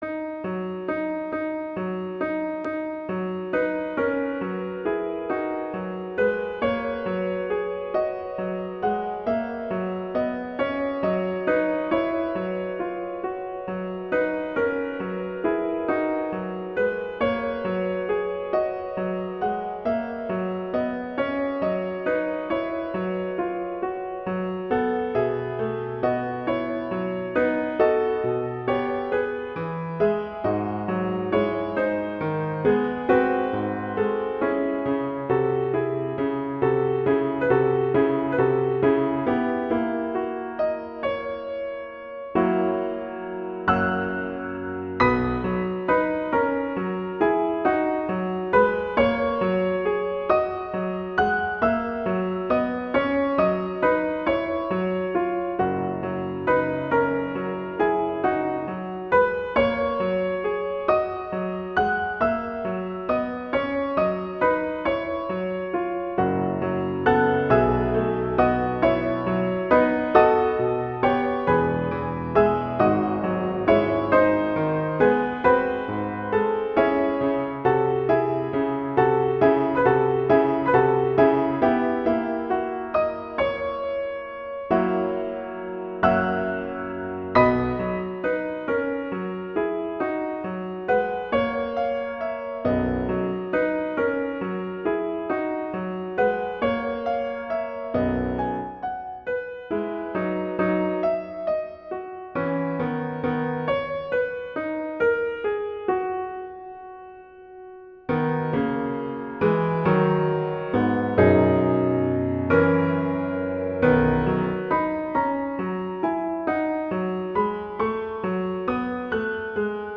classical, french
B major
♩. = 45 BPM (real metronome 44 BPM)